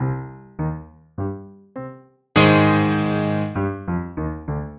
Звуки злодея
Темная мелодия пианино предвещающая появление злодея